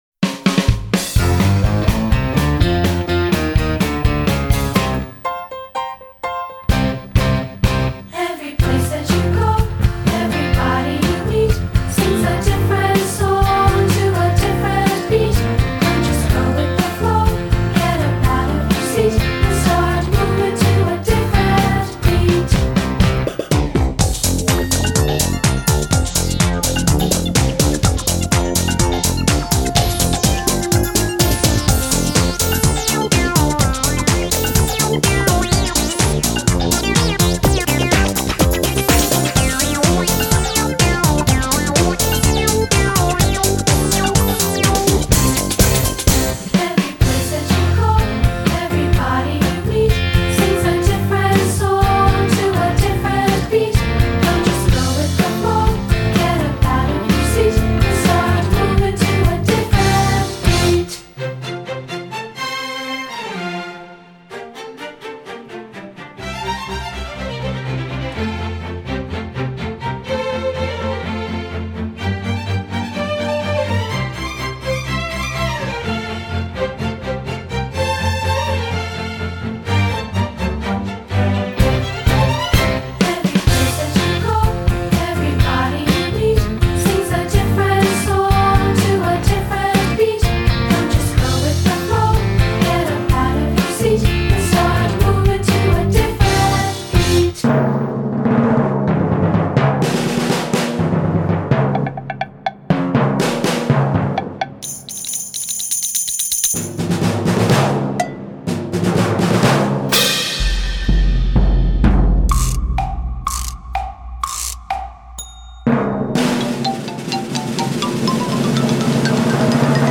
1a A Different Beat Vocal.mp3